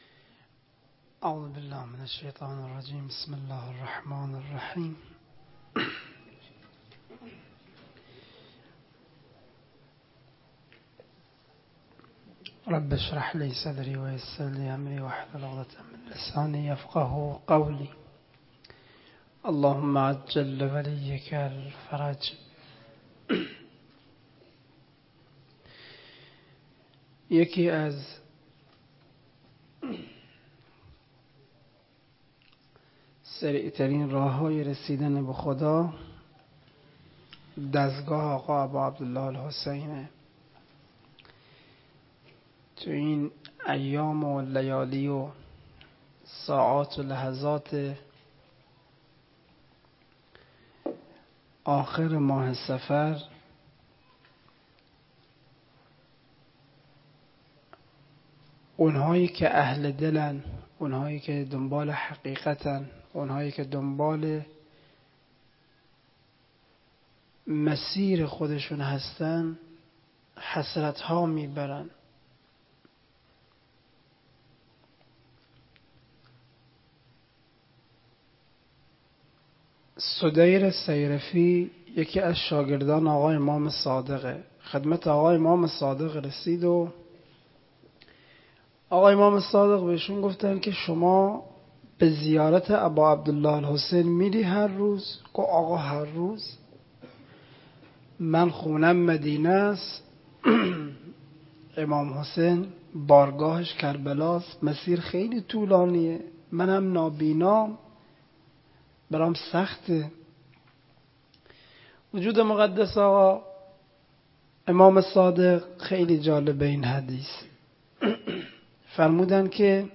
منبر